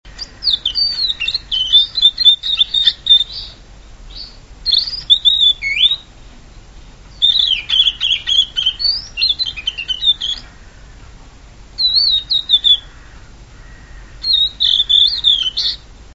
さえずりのコーナー（オオルリ編）
練習中！ 64KB メジロと一緒に飼っていたら、鳴き方が似てきた(T_T)